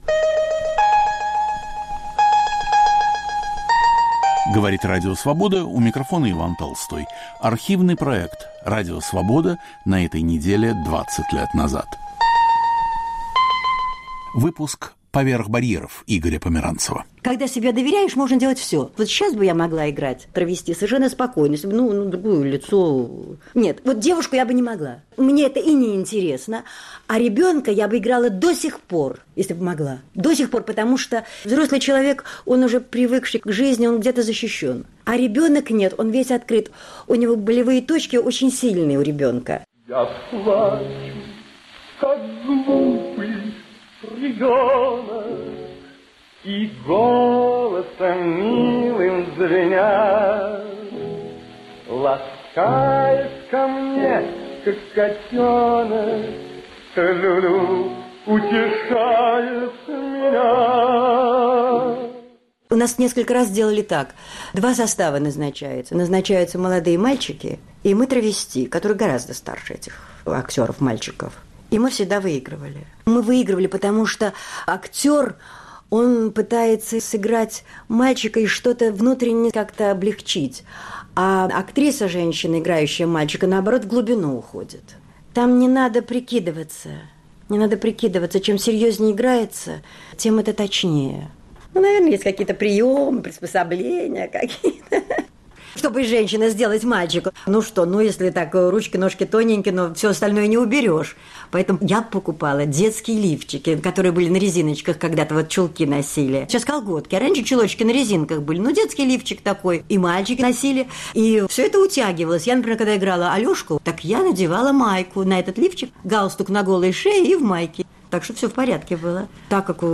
Рассказывают петербургские актрисы.